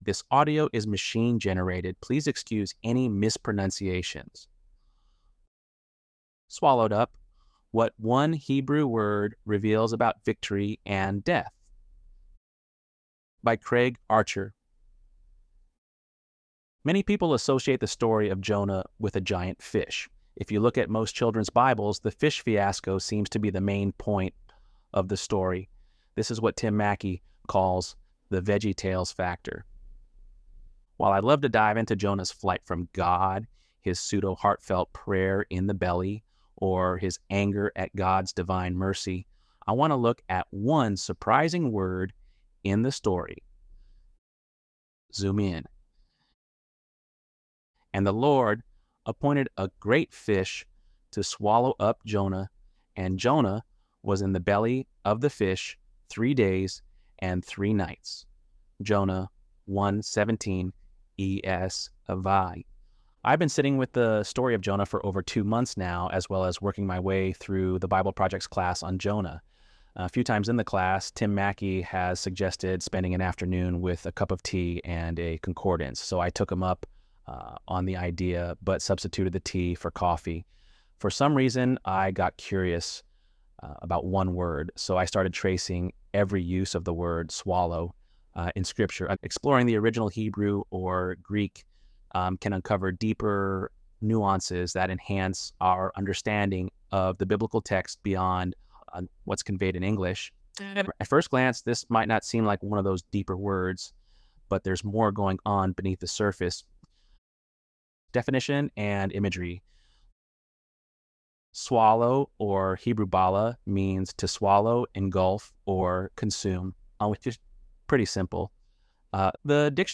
ElevenLabs_2_7.mp3